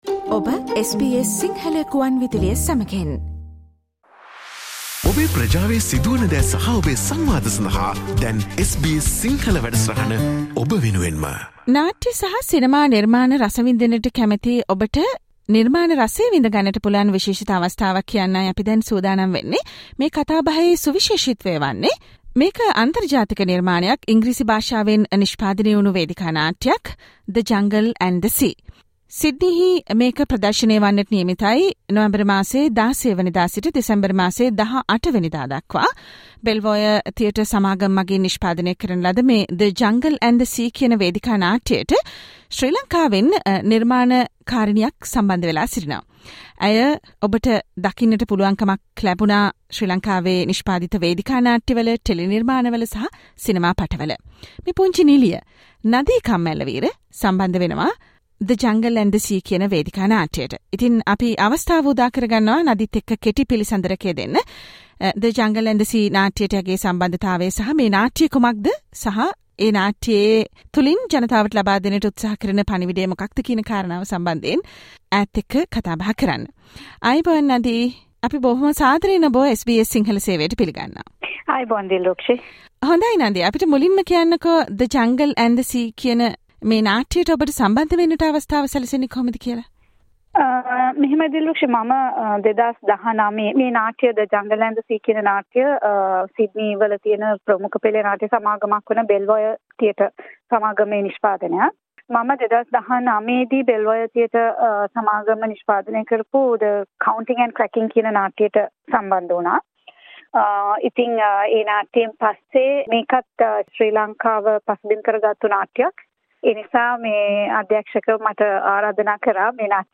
Listen to the discussion that SBS Sinhala Radio had with her...